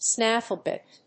アクセントsnáffle bìt